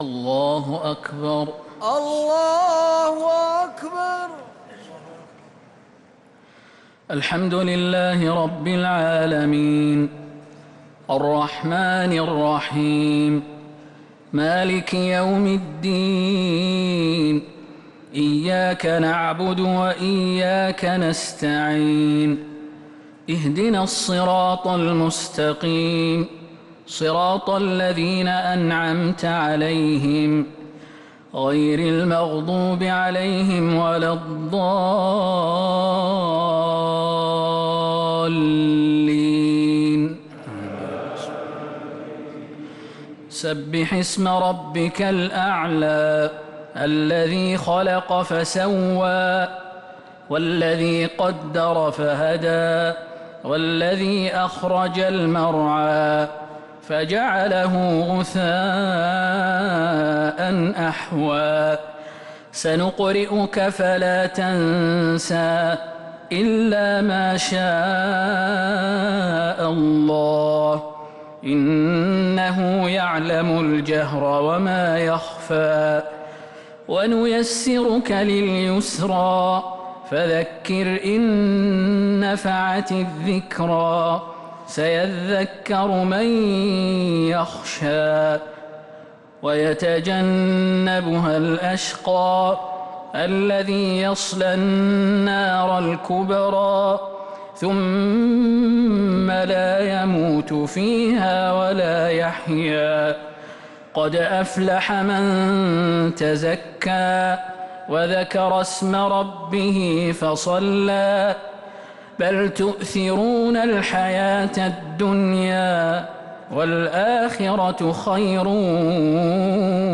الشفع و الوتر ليلة 15 رمضان 1447هـ | Witr 15th night Ramadan 1447H > تراويح الحرم النبوي عام 1447 🕌 > التراويح - تلاوات الحرمين